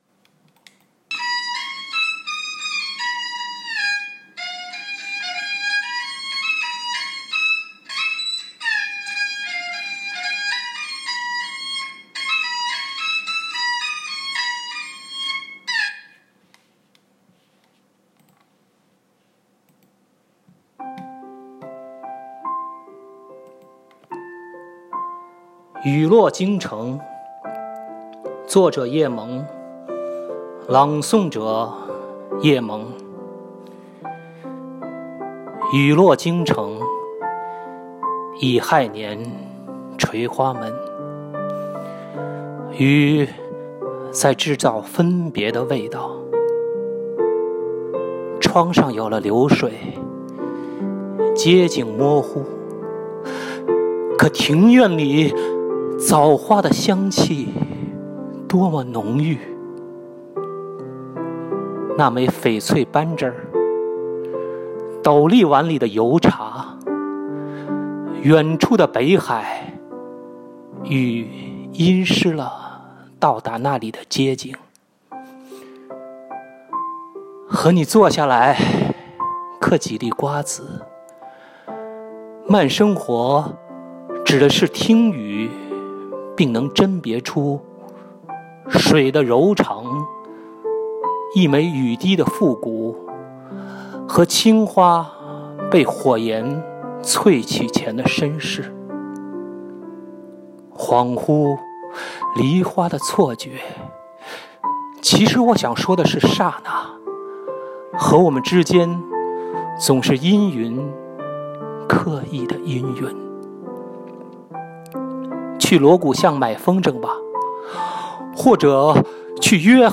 朗诵